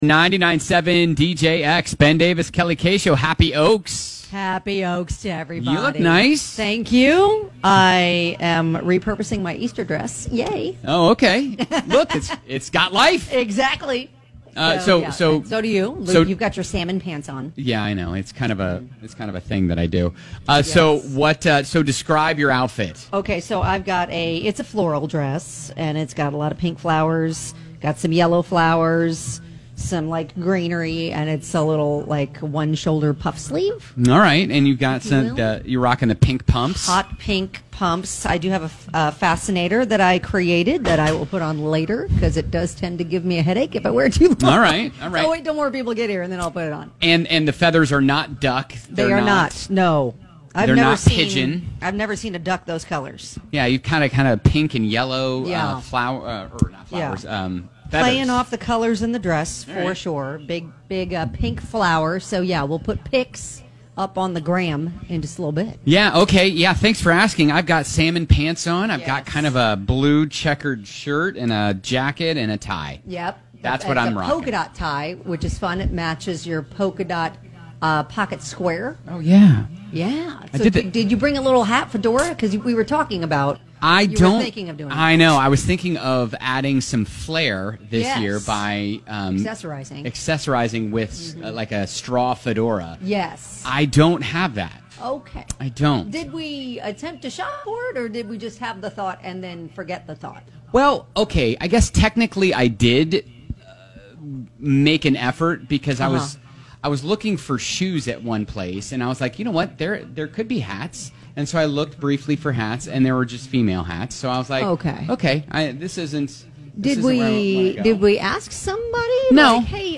05/06/2022 The One Live From Backside On Oaks